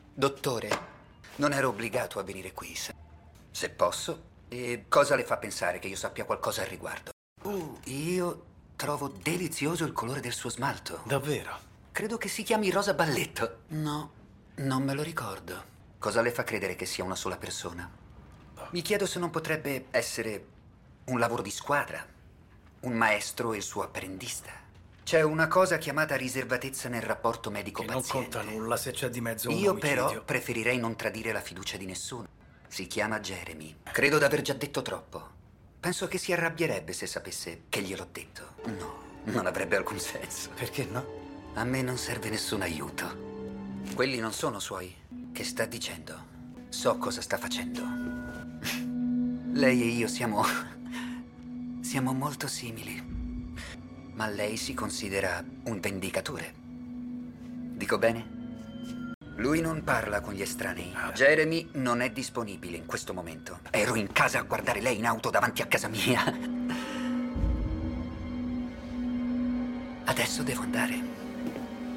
nel telefilm "Blue Bloods"